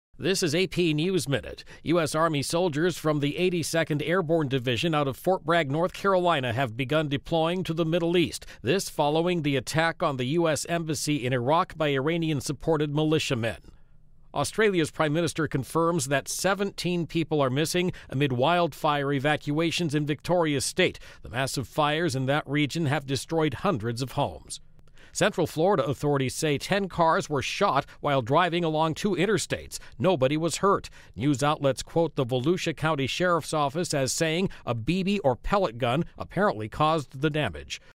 美语听力练习素材:澳大利亚山火肆虐